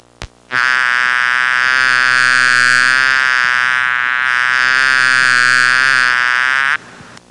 Big Mosquito Sound Effect
Download a high-quality big mosquito sound effect.
big-mosquito-1.mp3